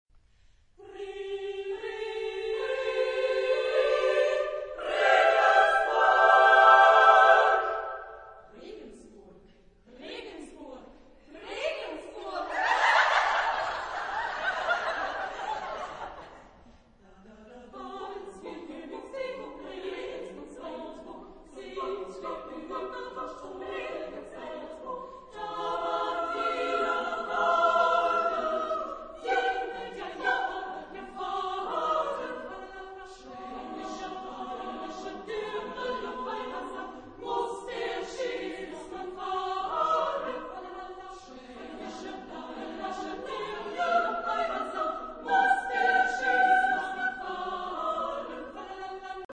Genre-Stil-Form: Volkslied ; Liedsatz ; weltlich
Chorgattung: SSSSAAAA  (8 Frauenchor Stimmen )
Tonart(en): C-Dur
Aufnahme Bestellnummer: 7. Deutscher Chorwettbewerb 2006 Kiel